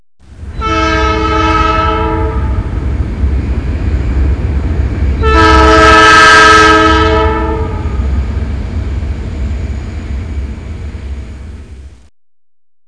train6.mp3